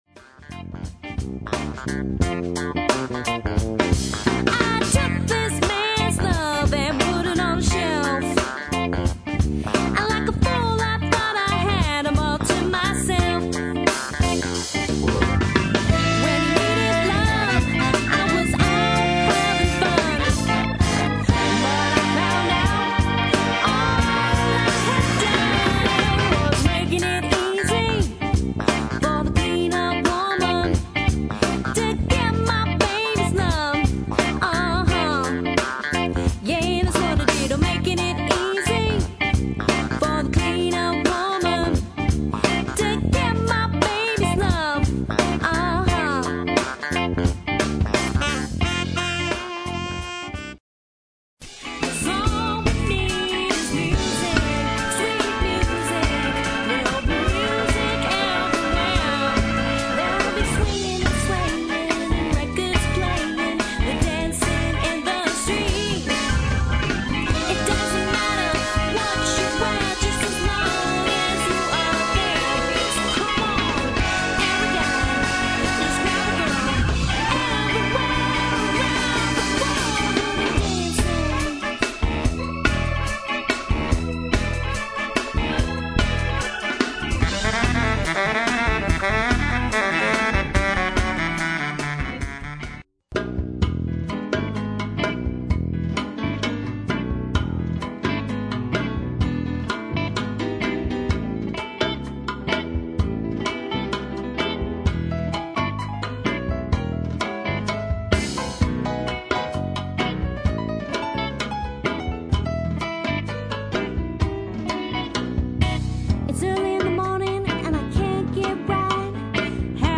plays Motown, Funk, Blues and R&B